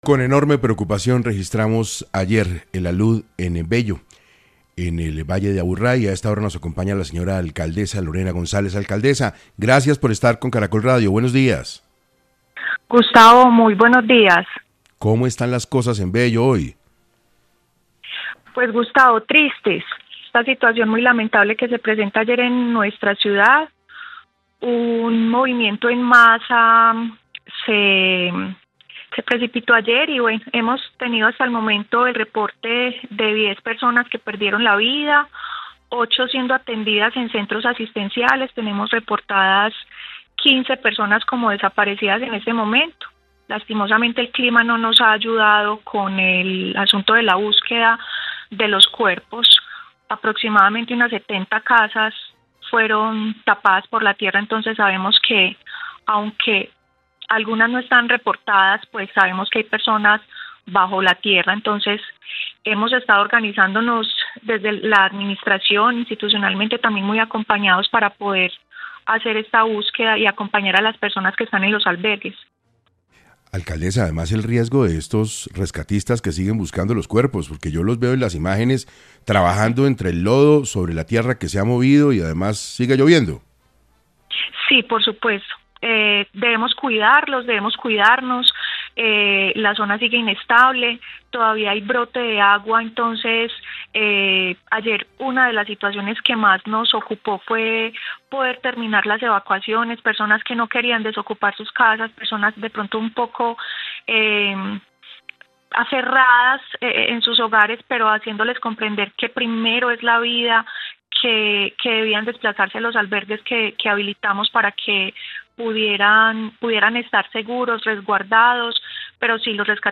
Lorena González, alcaldesa de Bello, Antioquia explicó en 6AM cómo avanza la búsqueda de los desaparecidos, debido a que no paran las intensas lluvias.